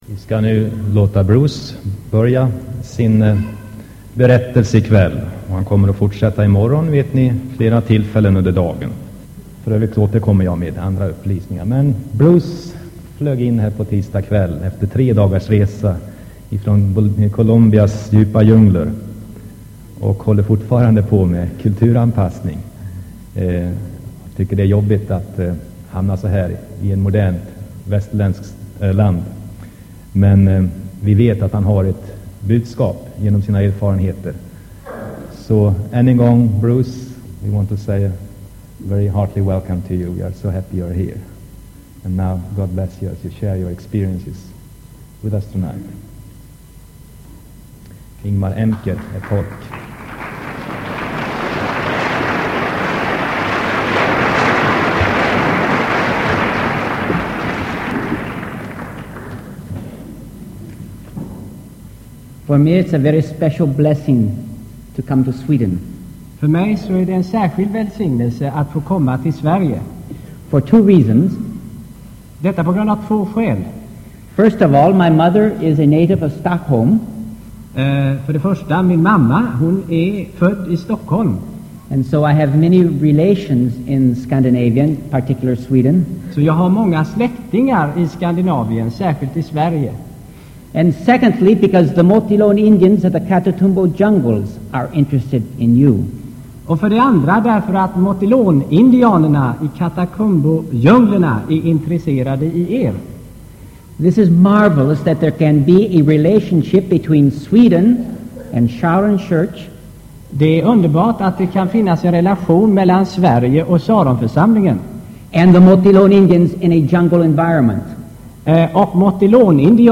Inspelad i Saronkyrkan, Göteborg 1984-04-20.